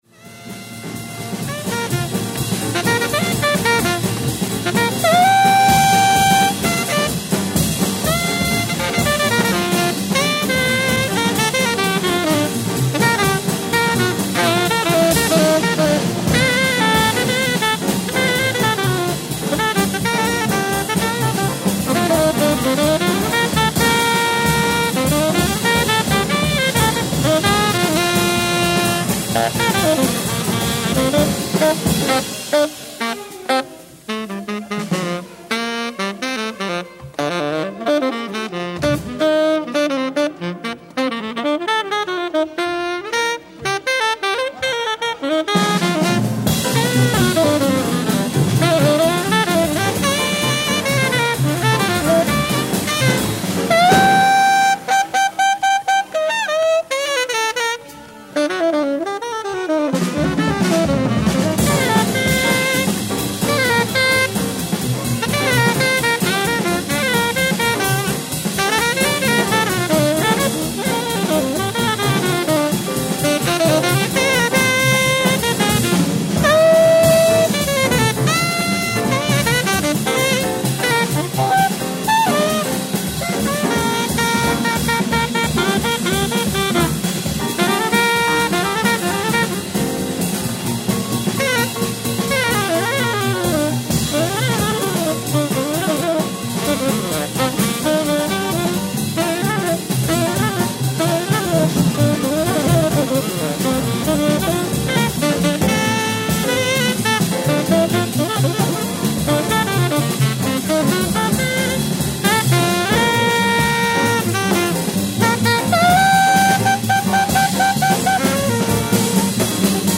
ライブ・アット・オッドフェロー・パレス、コペンハーゲン、デンマーク 04/14/1964
海外マニアのリマスター音源盤！！
※試聴用に実際より音質を落としています。